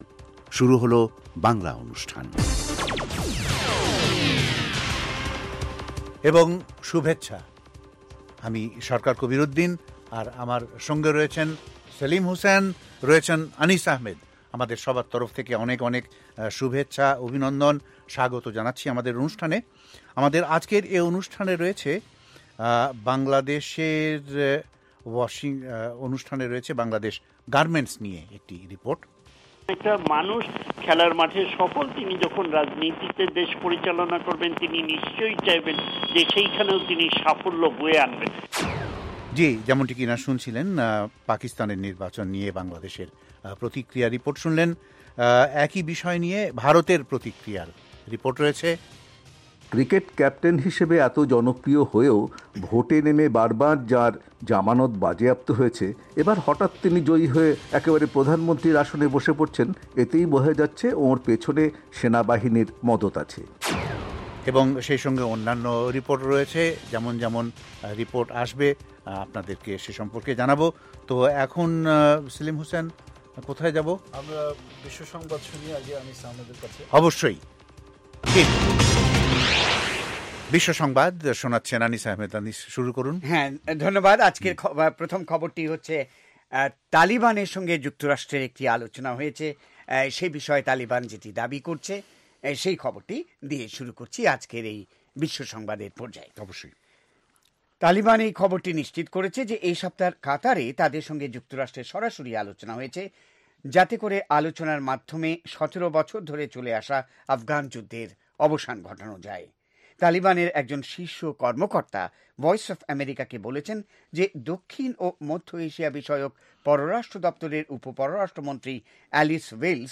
অনুষ্ঠানের শুরুতেই রয়েছে আন্তর্জাতিক খবরসহ আমাদের ঢাকা এবং কলকাতা সংবাদদাতাদের রিপোর্ট সম্বলিত ‘বিশ্ব সংবাদ’, এর পর রয়েছে ওয়ার্ল্ড উইন্ডোতে আন্তর্জাতিক প্রসংগ, বিজ্ঞান জগত, যুব সংবাদ, শ্রোতাদের চিঠি পত্রের জবাবের অনুষ্ঠান 'মিতালী' এবং আমাদের অনুষ্ঠানের শেষ পর্বে রয়েছে যথারীতি সংক্ষিপ্ত সংস্করণে বিশ্ব সংবাদ।